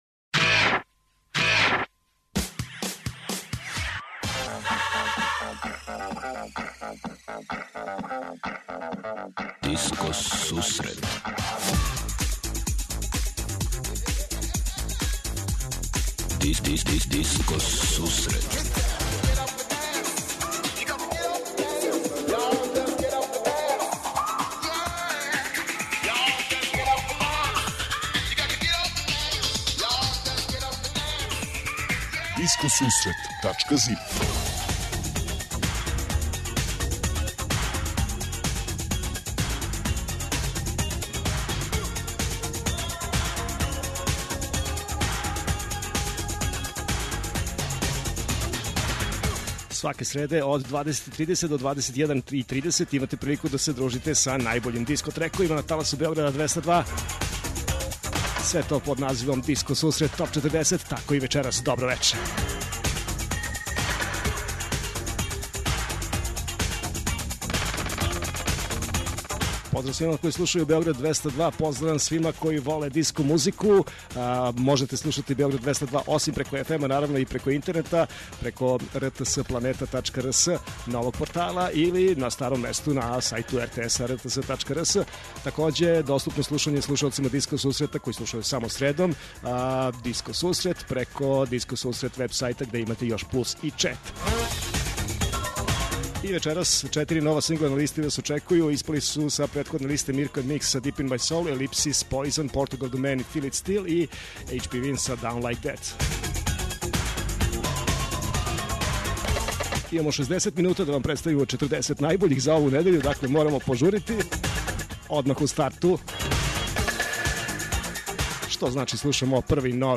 Eмисија посвећена најновијој и оригиналној диско музици у широком смислу.
Заступљени су сви стилски утицаји других музичких праваца - фанк, соул, РнБ, итало-диско, денс, поп. Сваке среде се представља најновија, актуелна, Топ 40 листа уз непосредан контакт са слушаоцима и пуно позитивне енергије.